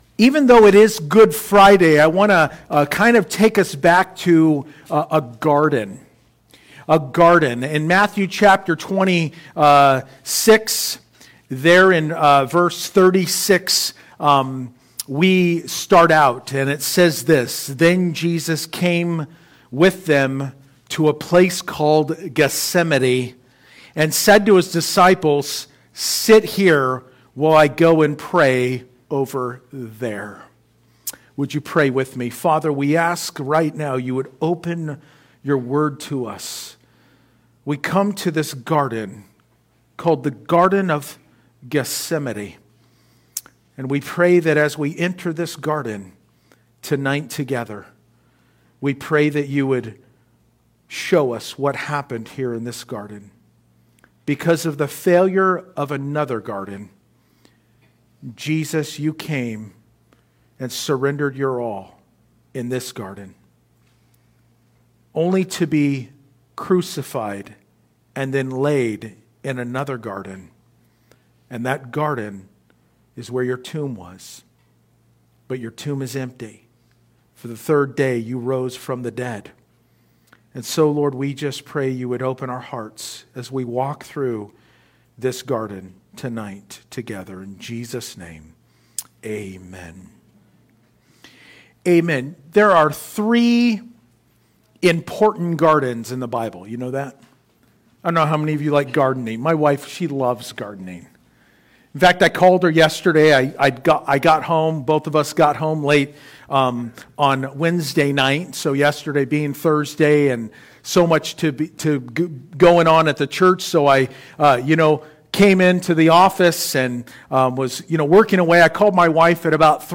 Good Friday Service, Matthew 26:36